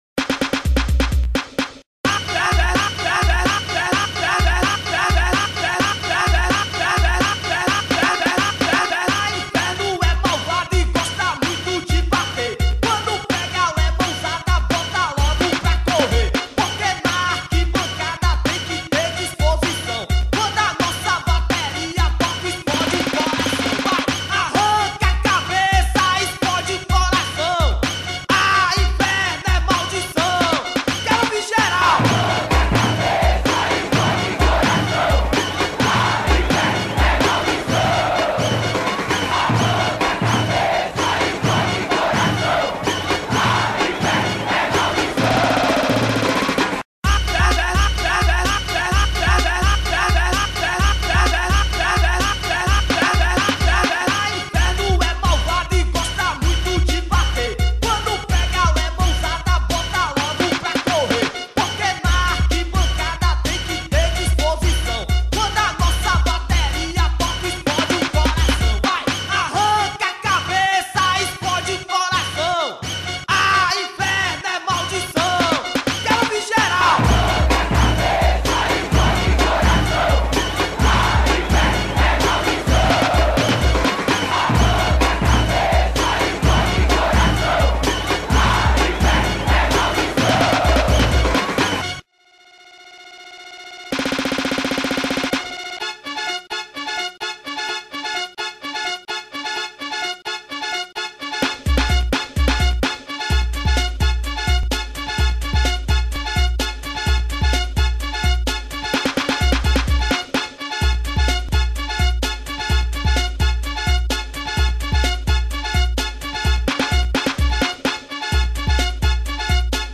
2024-12-16 22:38:14 Gênero: Rap Views